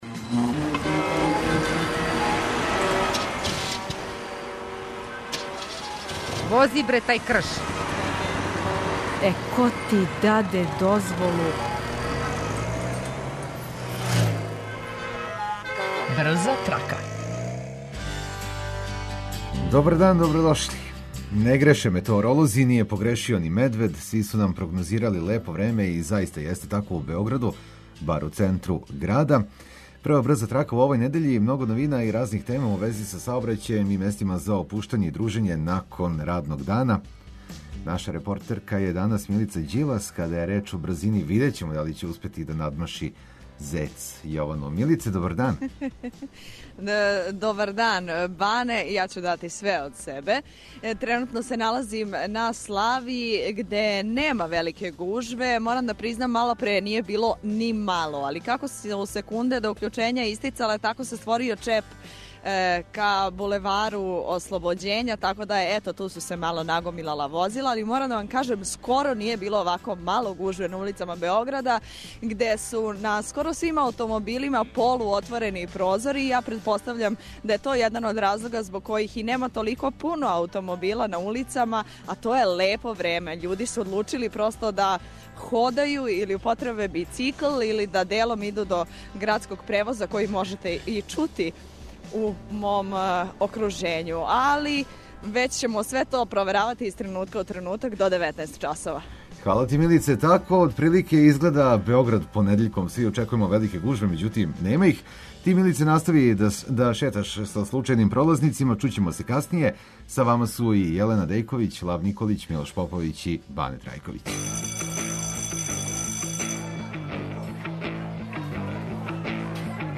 Слушаоци репортери нам јављају своја запажања, а свакодневно ту је и питање за размишљање.